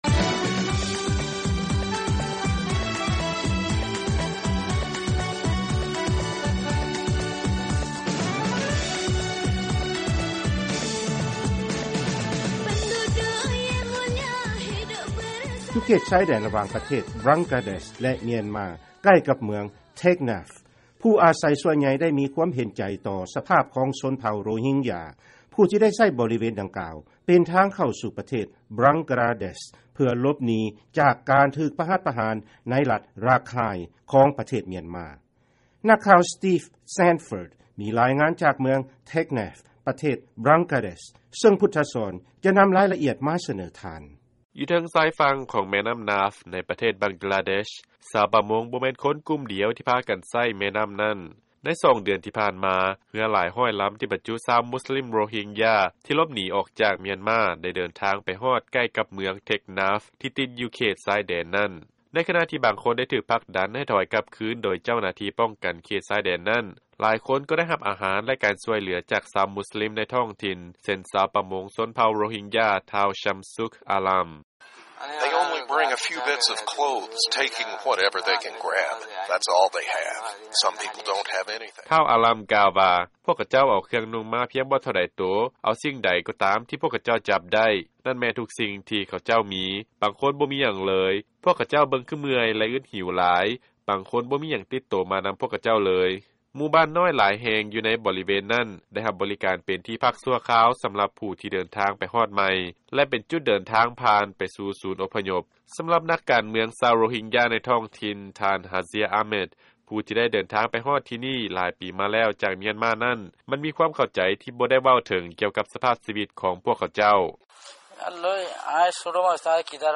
ຟັງລາຍງານ ຊາວ ໂຣຮິງຢາ ຫາຄວາມຊ່ວຍເຫຼືອ ແລະ ຄວາມເຫັນໃຈໃນປະເທດ ບັງກລາແດັສ